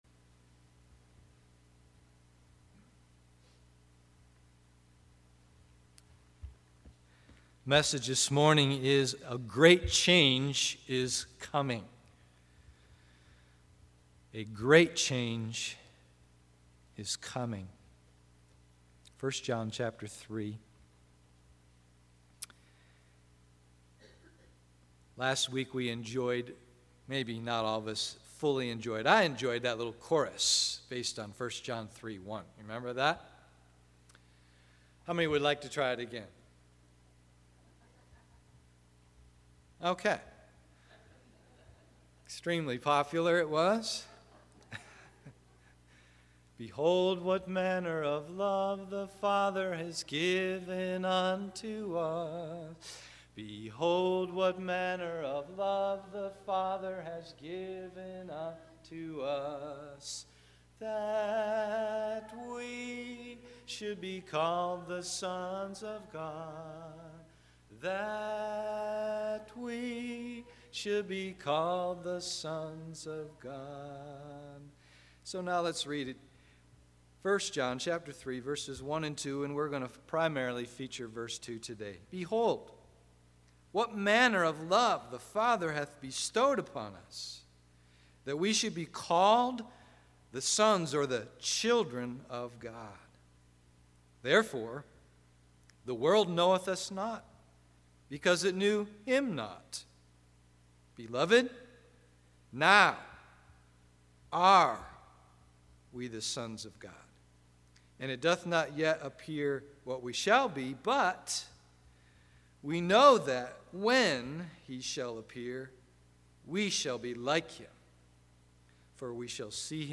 A Great Change is Coming AM Service